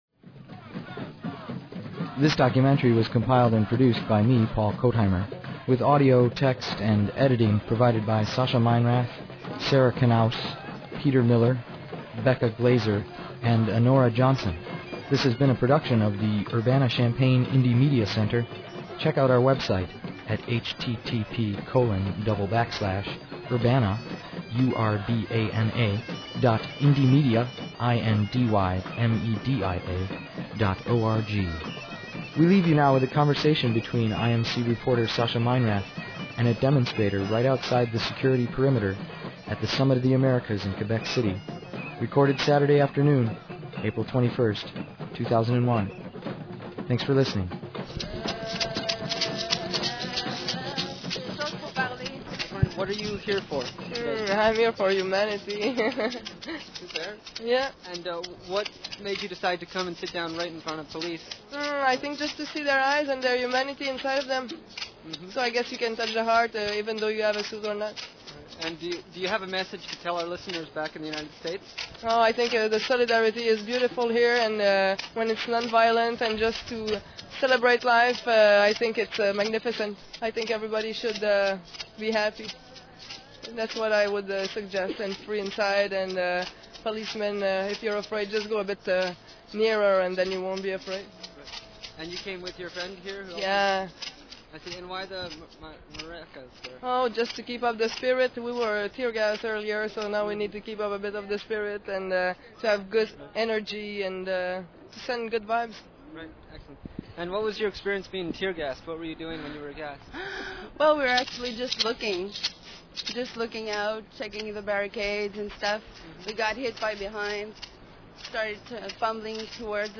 Just days after arriving home from Quebec City and the protests surrounding the Summit of the Americas, Urbana-Champaign Independent Media Center journalists compiled a half-hour document of their journey and experiences on the streets of Quebec City. Originally aired April 30 on community radio WEFT, during the IMC Newshour.